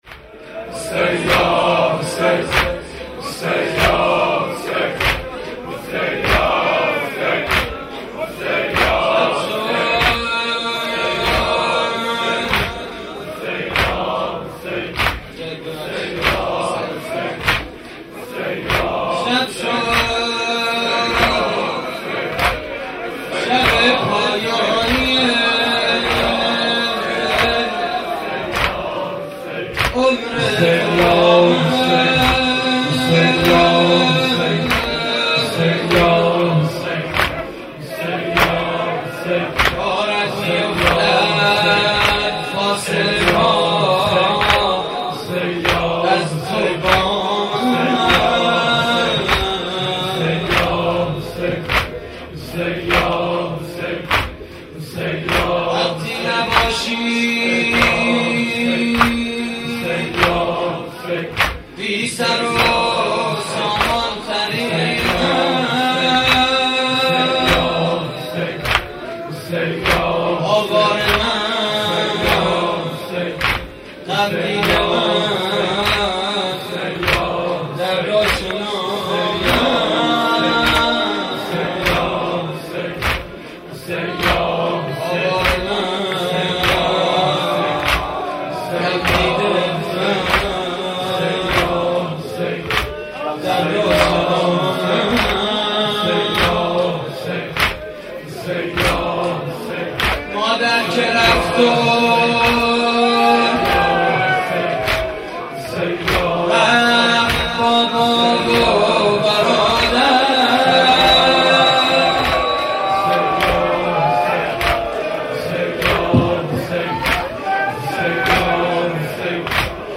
مراسم عزاداری شب عاشورای حسینی (محرم 1432) / هیئت کریم آل طاها (ع) – نازی آباد؛